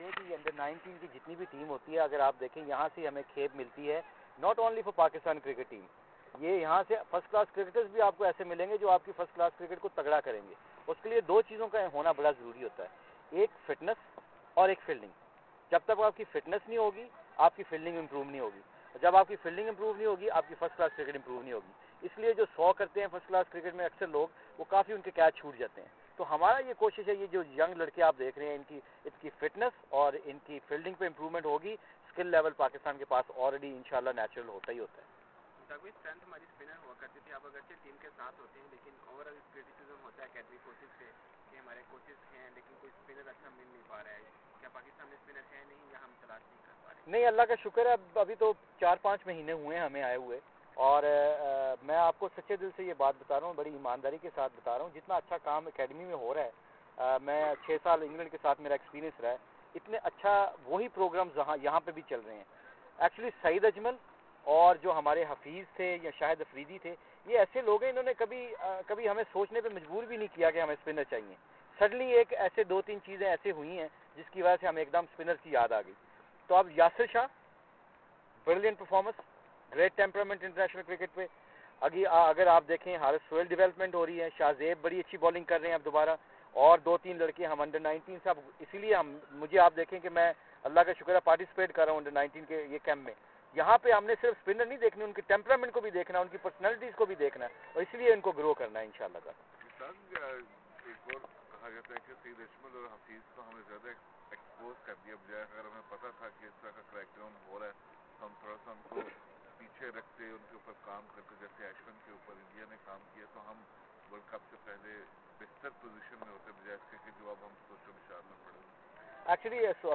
Mushtaq Ahmed media talk at Gaddafi Stadium Lahore